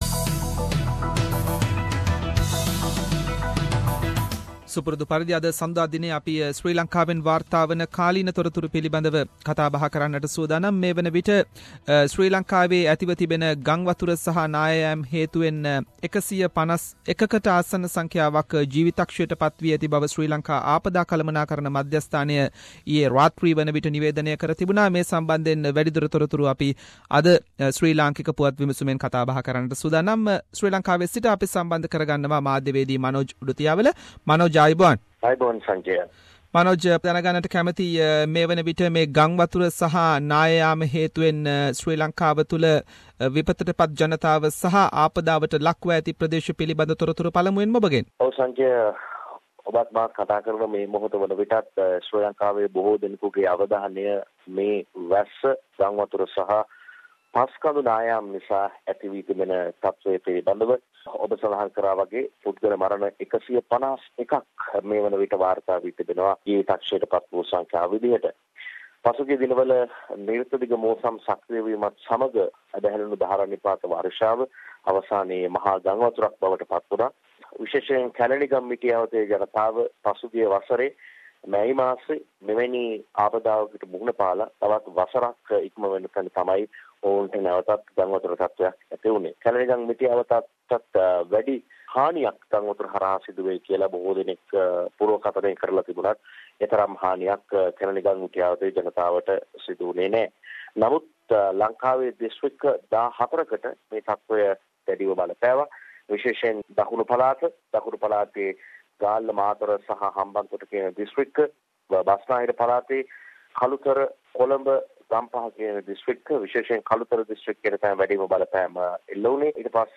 Latest on disaster situation in Sri Lanka - Weekly Sri Lankan news Wrap